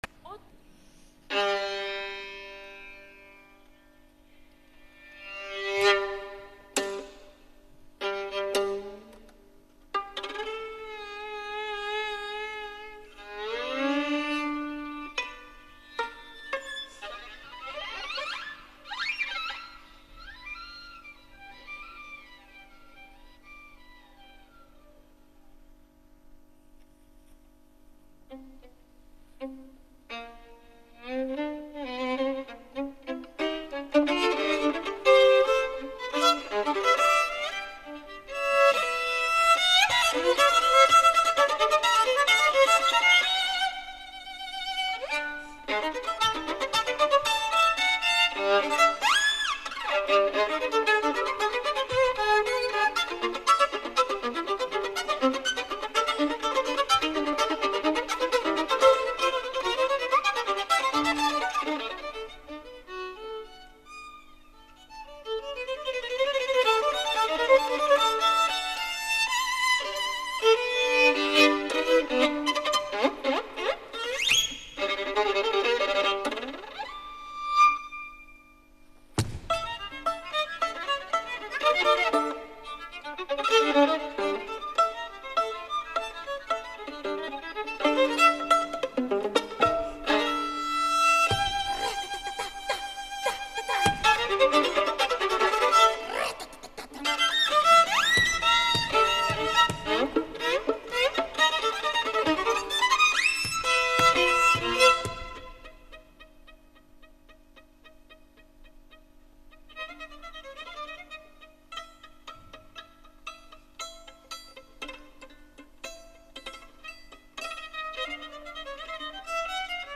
L’Auditori de Barcelona diumenge 27 de febrer de 2011